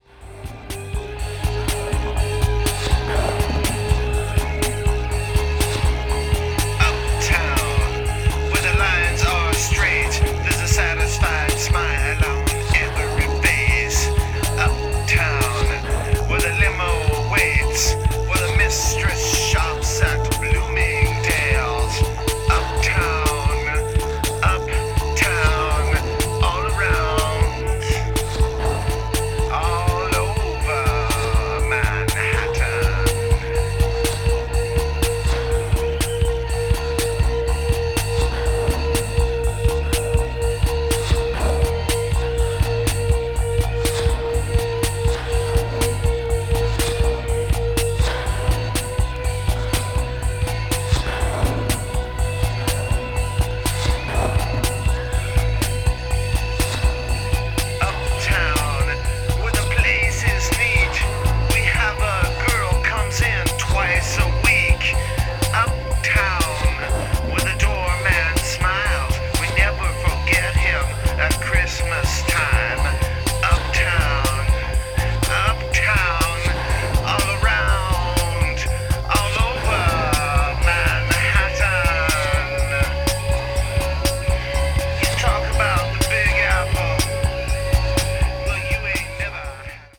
media : EX/EX(わずかにチリノイズが入る箇所あり)
experimental   leftfield   new wave   post punk